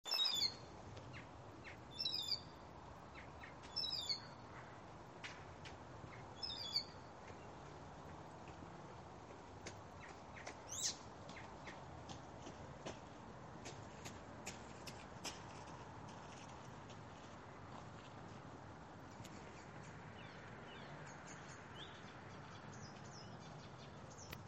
пересмешка, Hippolais icterina
Administratīvā teritorijaRīga
Ziņotāja saglabāts vietas nosaukumsPie Dvīņu ūdenstorņiem
СтатусПоёт